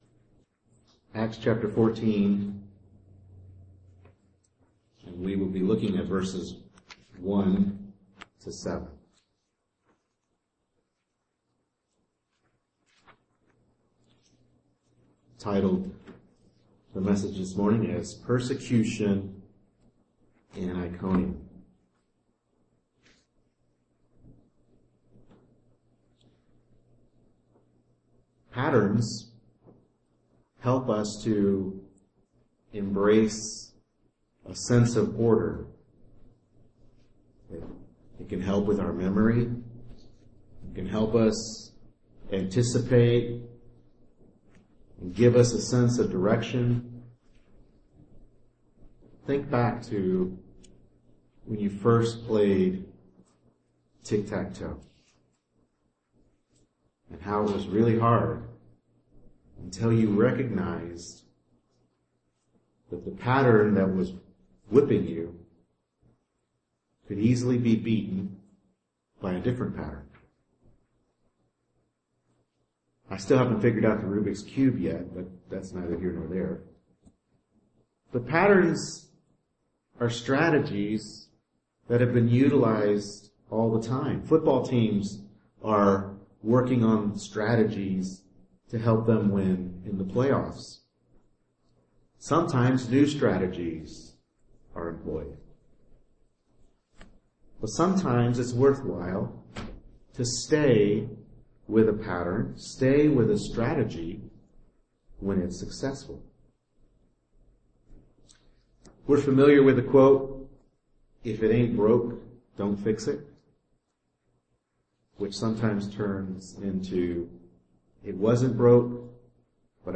Service Type: Morning Worship Service